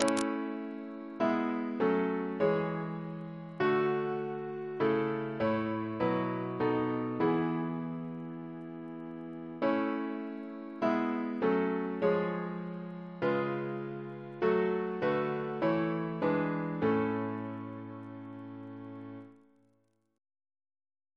Double chant in A Composer: Richard Massey (1798-1883) Reference psalters: ACP: 281